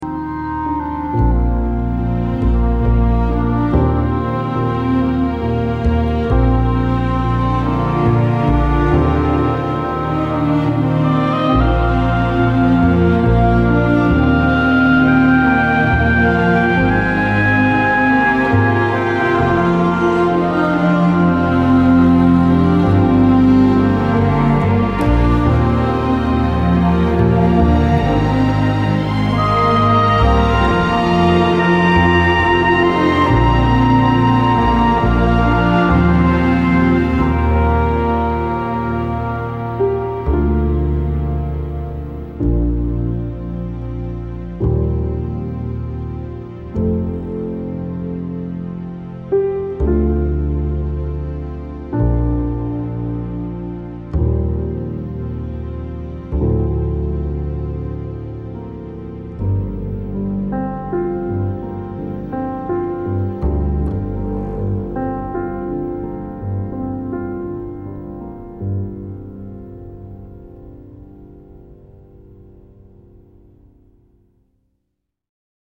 The mysterious and bone-chilling score